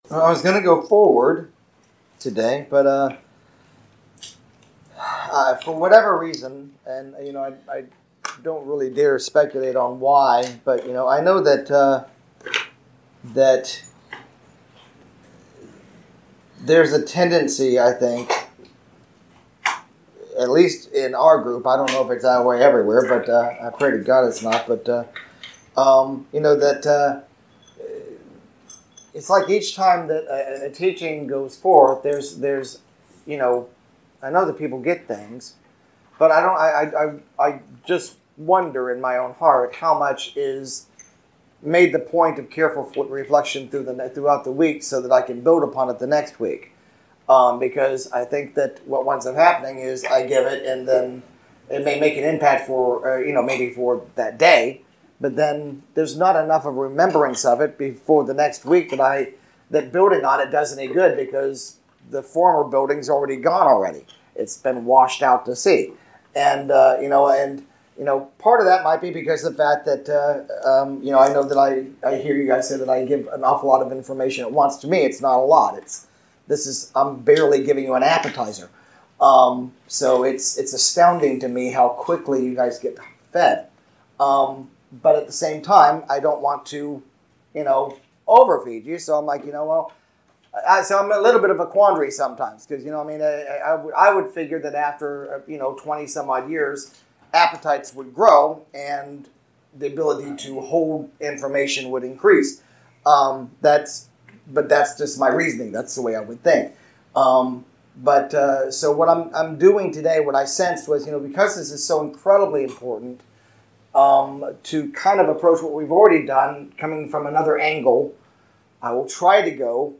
Sunday 09/08/19 Series: Walking the Talk Message – When the Spirit Grieves